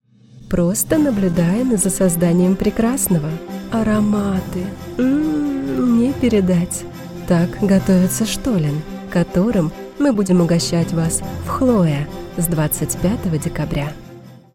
Жен, Другая/Средний
Приятный и нежный голос с хорошей дикцией.